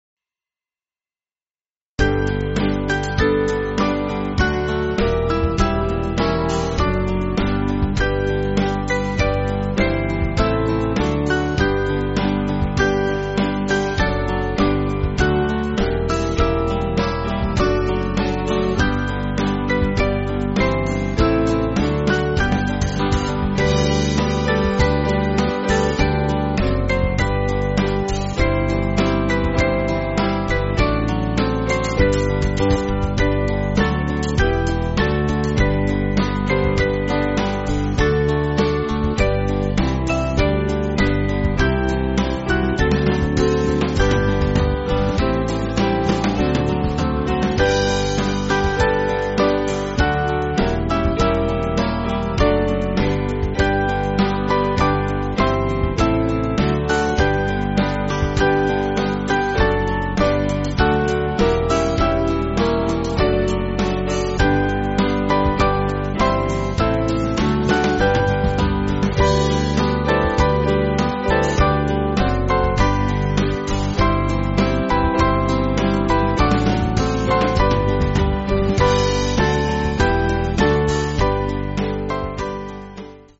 Small Band